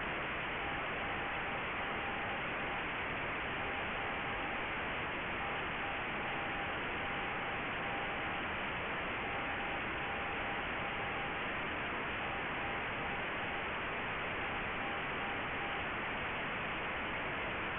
He made already a program in Python that does create a WAV file of a Morsecode signal and adds noise.
Morsecode test signals!
The speed is 6 words per minute (dot time 0.2 sec.).
Audio file -10 dB SNR, 6 words per minute